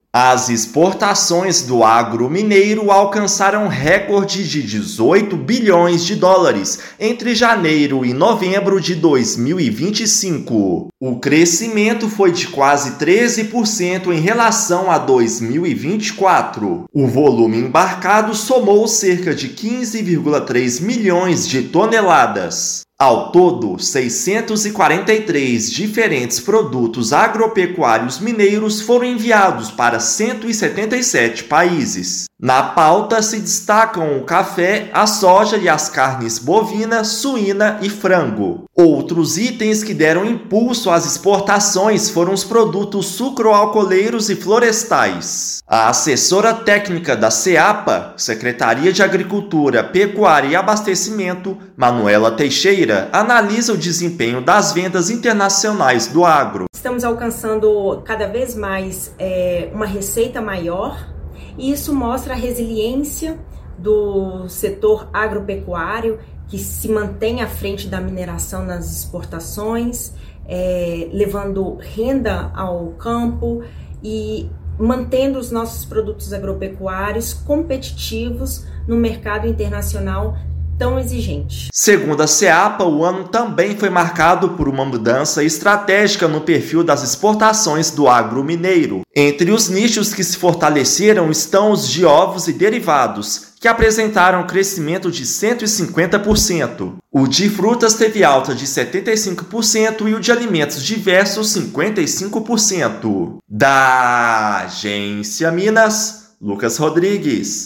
Valor do período acumulado de janeiro a novembro já é maior do que o registrado em 2024. Ouça matéria de rádio.